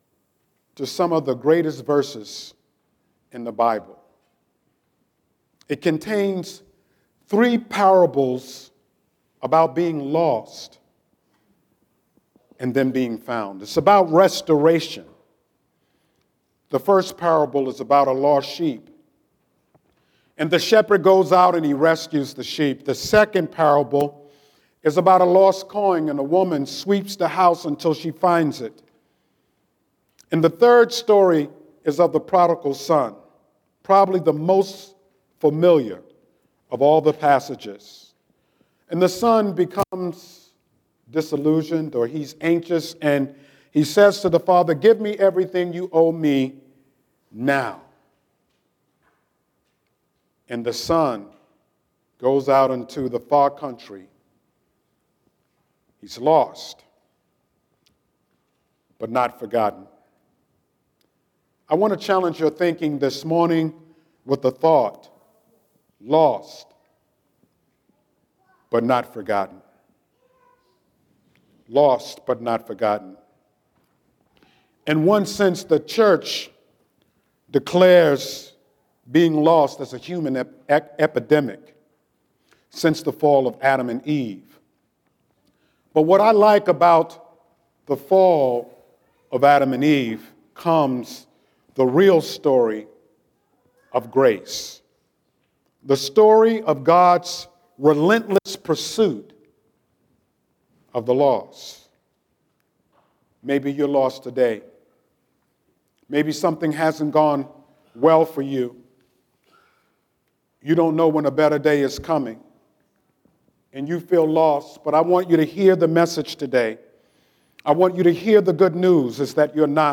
09-11-Scripture-and-Sermon.mp3